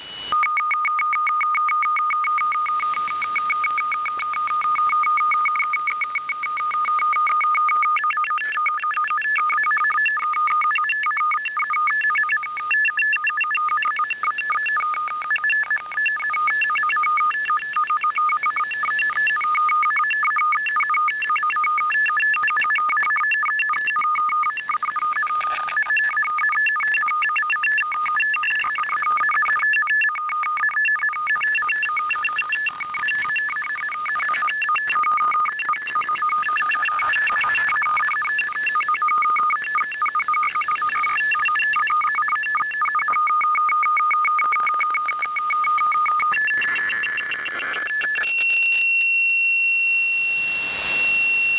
Начало » Записи » Радиоcигналы на опознание и анализ
Работа в режиме ДЧТ
mfsk_4_dct.wav